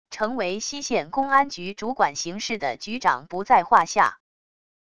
成为溪县公安局主管刑事的局长不在话下wav音频生成系统WAV Audio Player